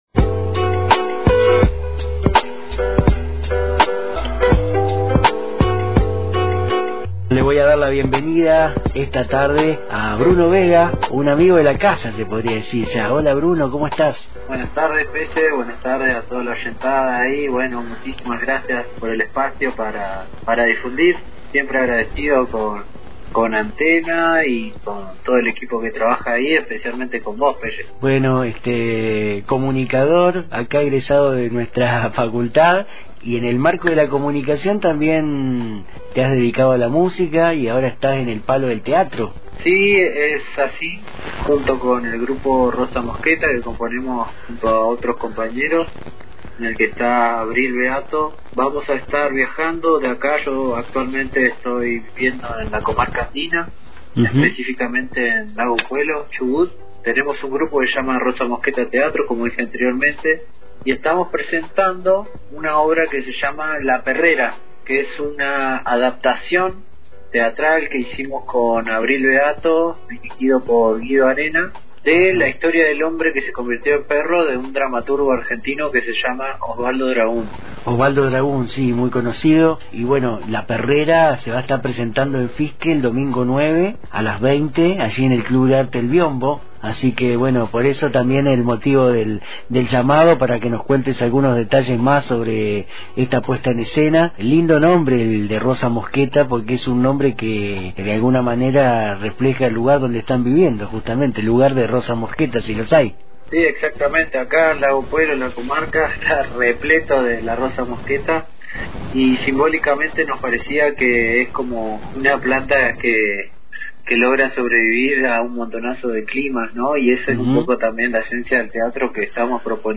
En esta nota telefónica